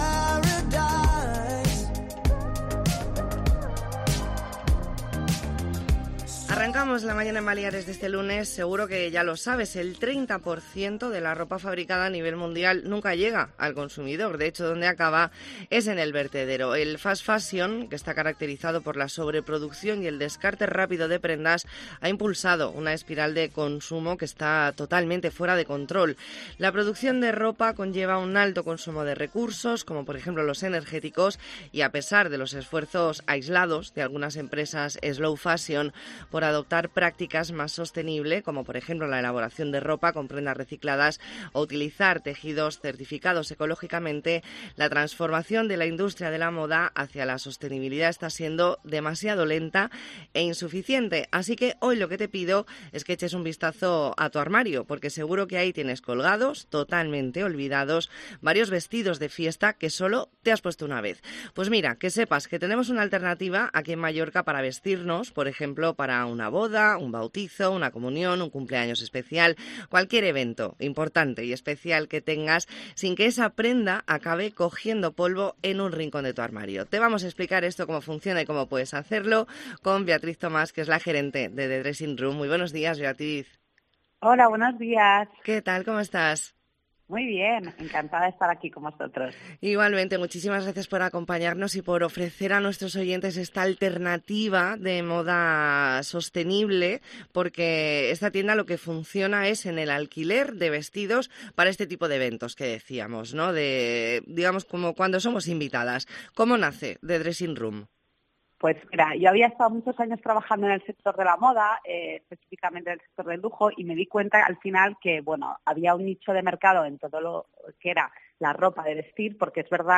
Entrevista en La Mañana en COPE Más Mallorca, lunes 9 de octubre de 2023.